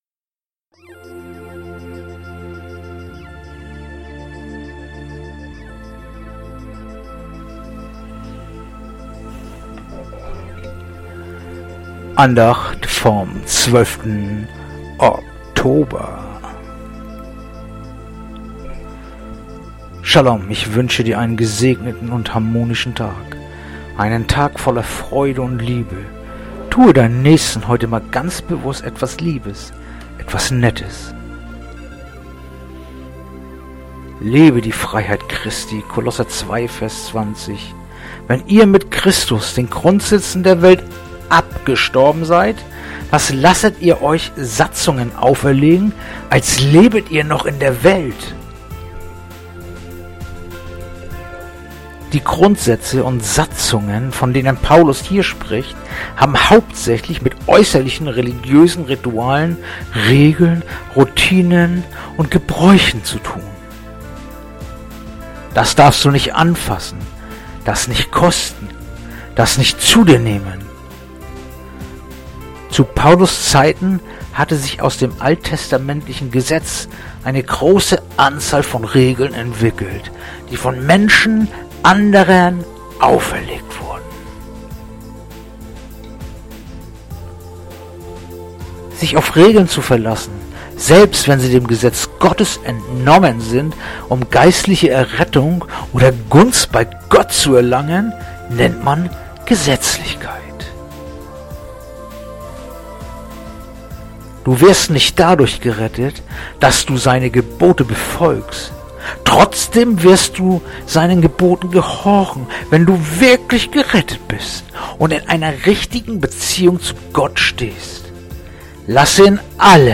heutige akustische Andacht
Andacht-vom-12-Oktober-Kolosser-2-20.mp3